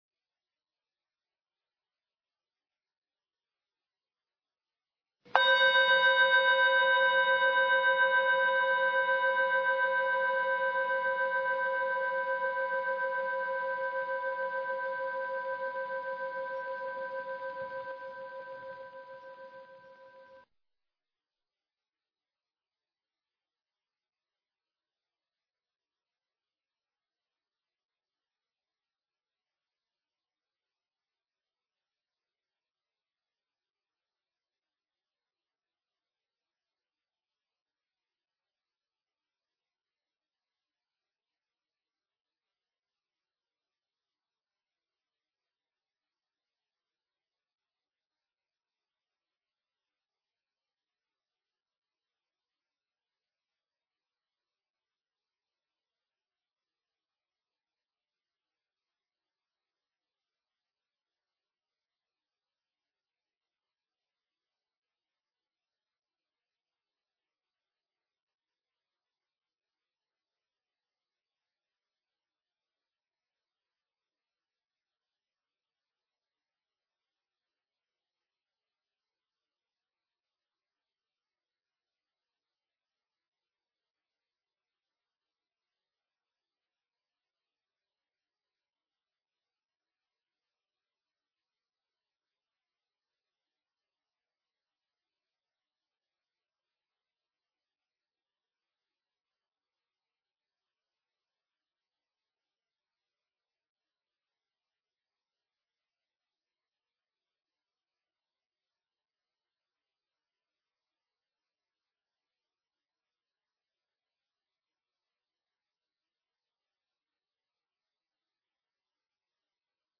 Tiếng chuông đầu tiên thông báo để vào thư giản và điều thân. 2.
Tiếng chuông thứ nhì thông báo vào thư giản và Quán số điện tử. 3.
Ba tiếng chuông liên tiếp cuối cùng là Hồi Hướng và Xả Thiền.